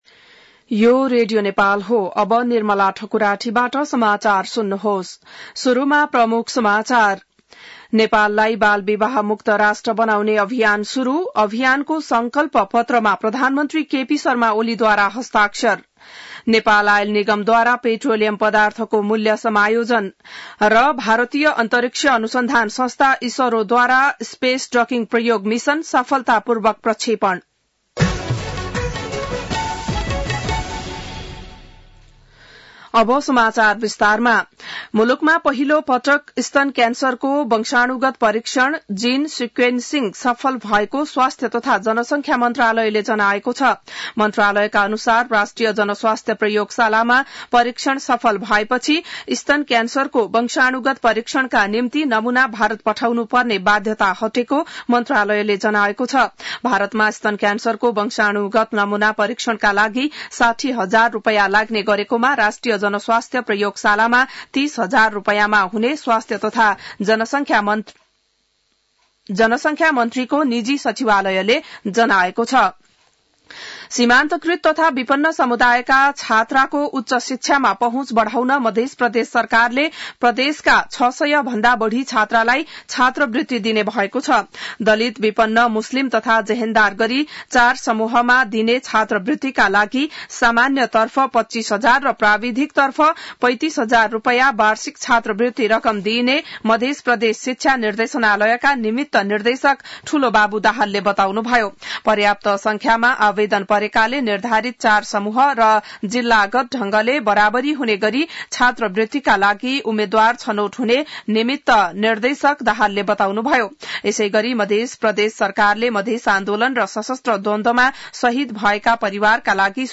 बिहान ९ बजेको नेपाली समाचार : १८ पुष , २०८१